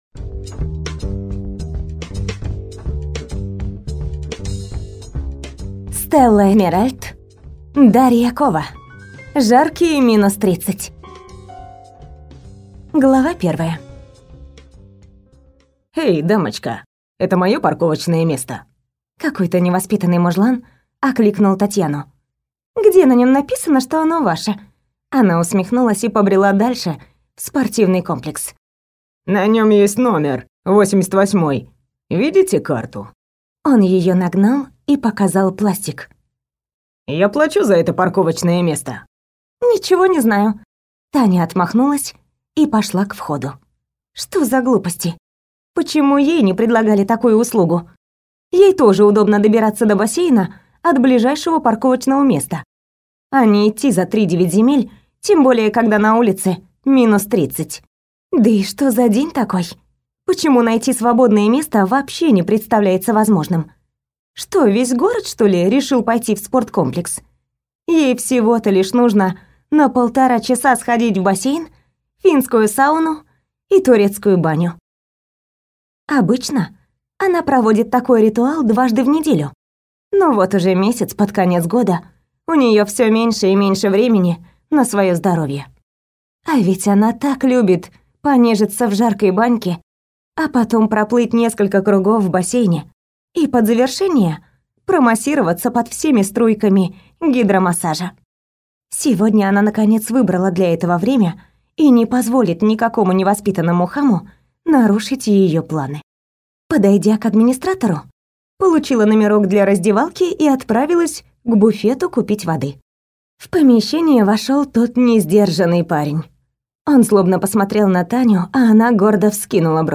Аудиокнига Жаркие минус 30 | Библиотека аудиокниг